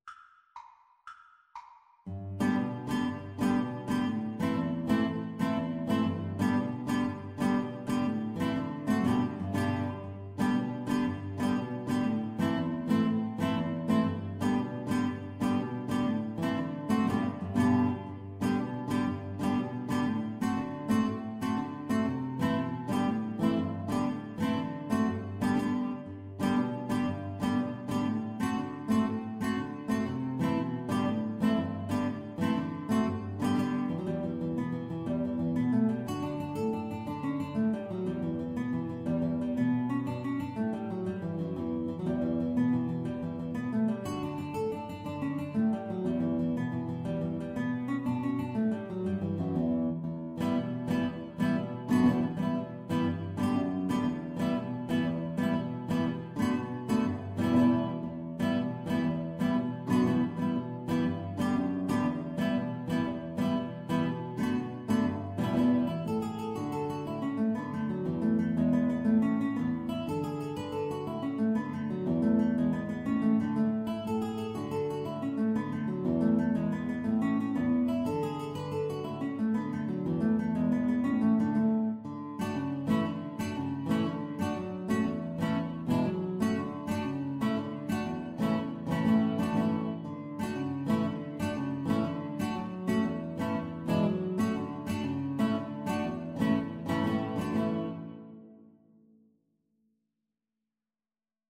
Moderato . = 120
6/8 (View more 6/8 Music)